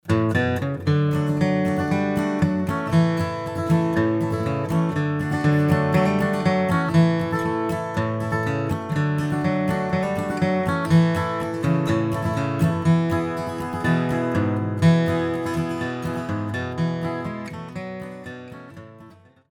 Hier ein Bluegrass-Standard,
John Hardy, das ist ein Soloteil, in dem wiederum die Gesangsmelodie angedeutet wird.
flatpicking_bluegrass_melody.mp3